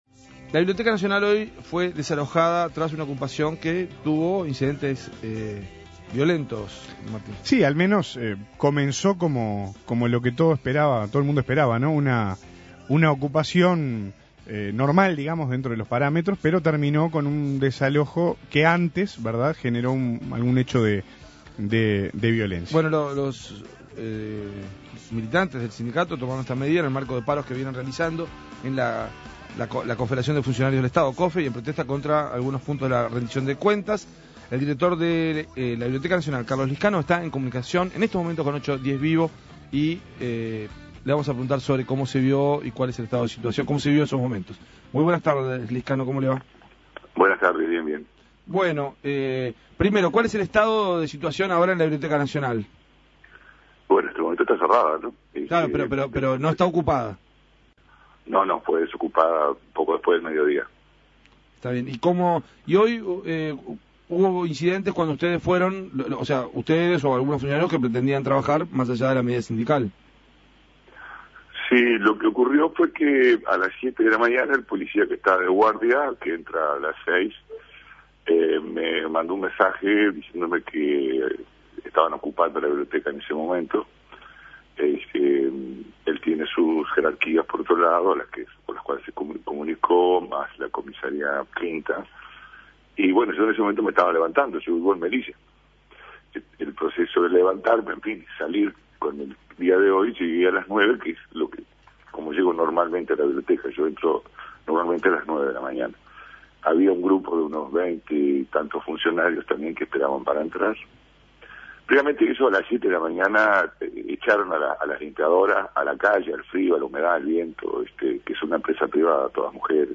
Escuche la entrevista a Carlos Liscano